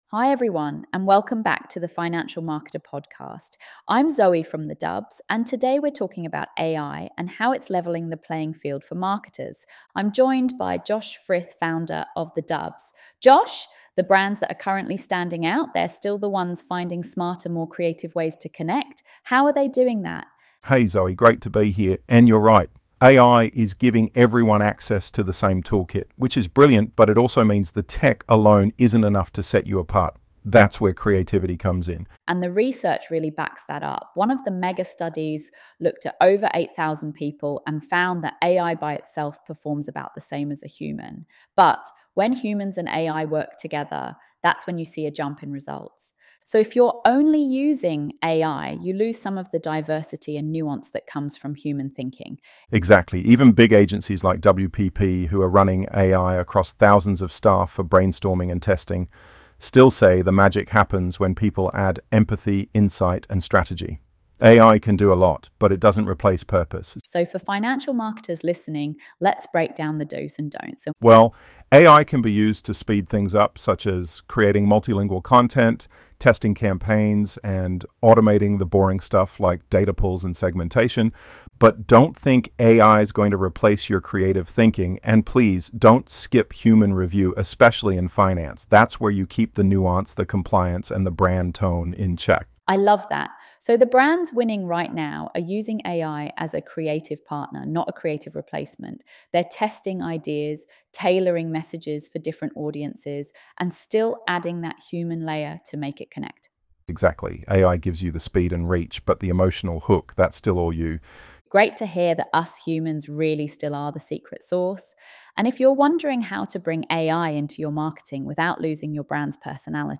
AI levels the field but creativity still wins (AI podcast) In today’s fast‑moving financial marketing world, AI tools are making powerful capabilities accessible to all: content generation, data analysis, localisation at scale, automation.
ElevenLabs_AI_levels_the_field_but_creativity_still_wins-1.mp3